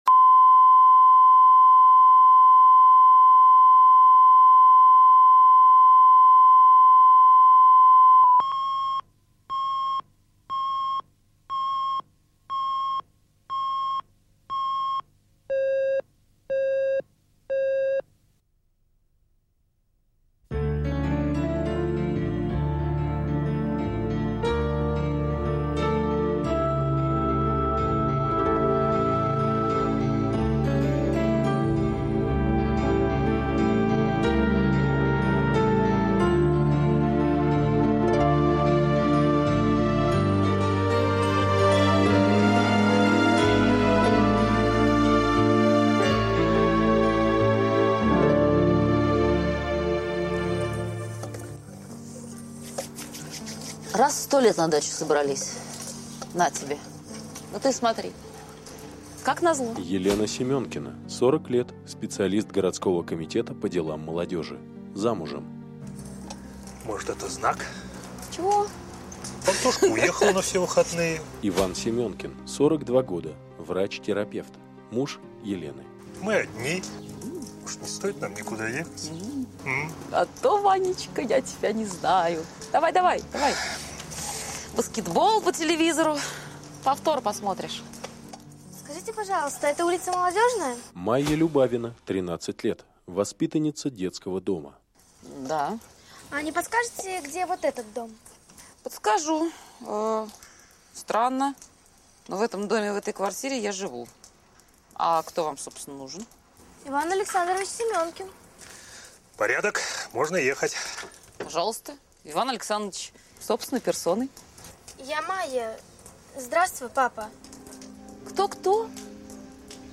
Аудиокнига Здравствуй, папа | Библиотека аудиокниг